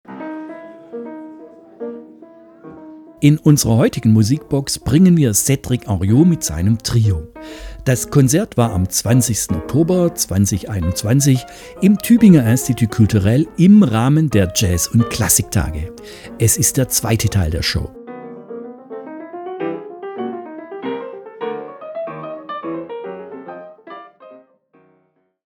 piano, computer, vocoder
bass
drums